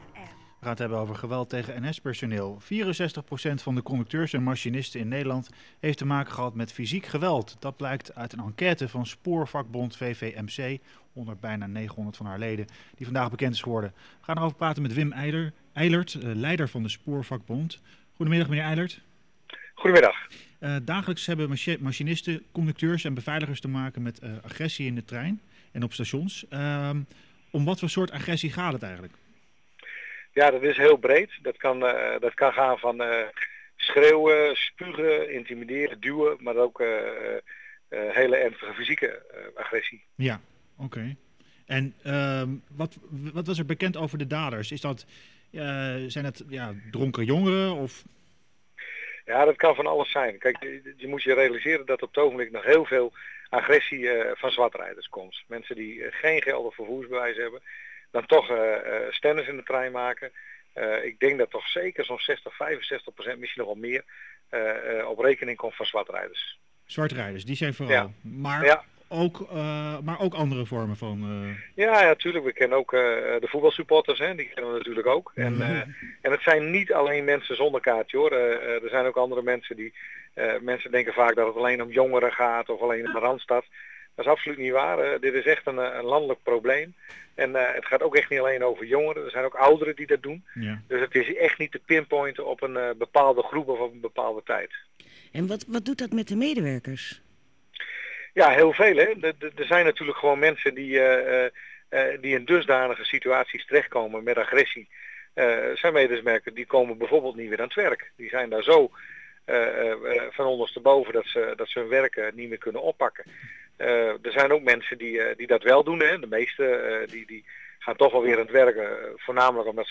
Ritme van de Stad een gesprek